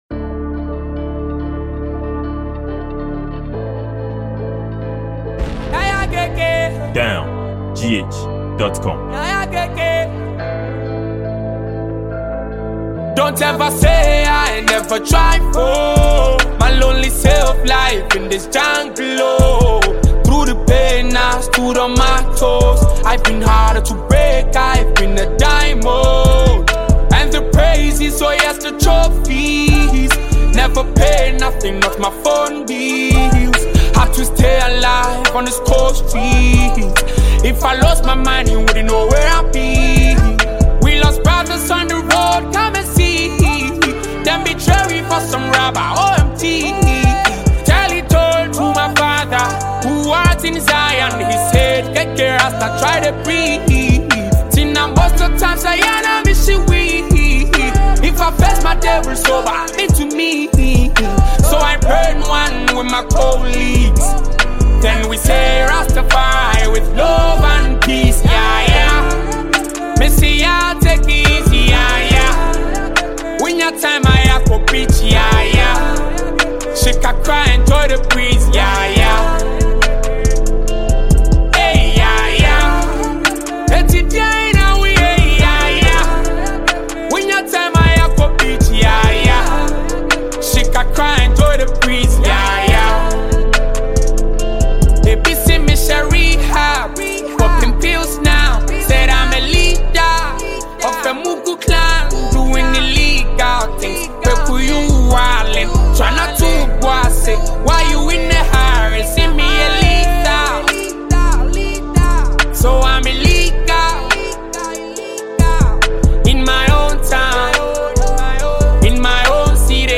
Ghana Music
Ghanaian vocals and talented singer